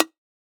edm-perc-34.wav